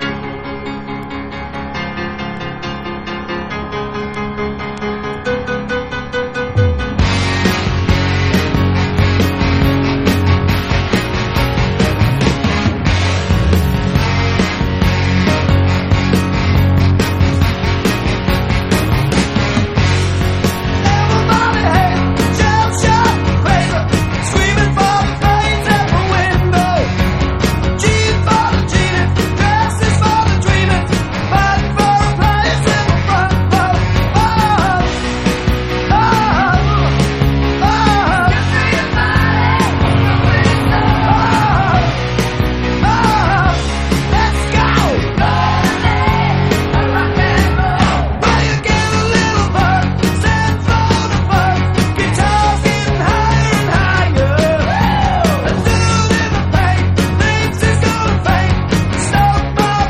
ROCK / 90''S～ / DISCO / DANCE CLASSIC